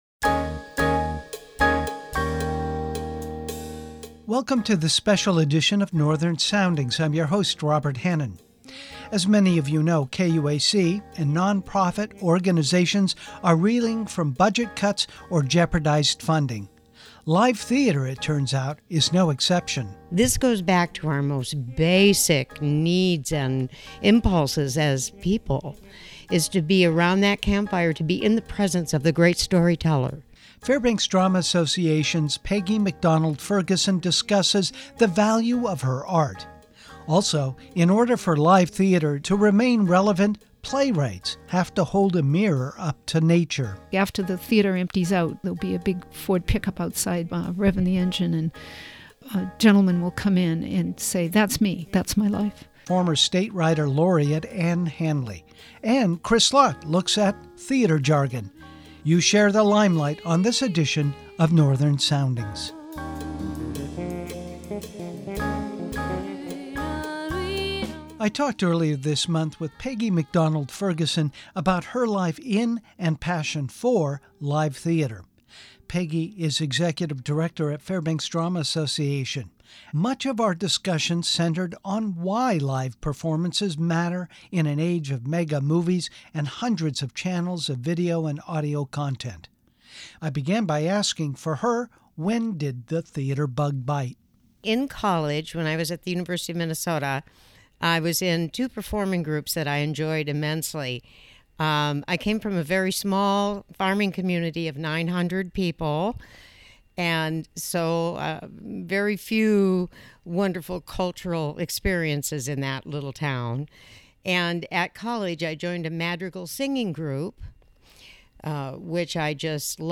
Also on the show is a reprise of a discussion I had two years ago with former Alaska Writer Laureate Anne Hanley.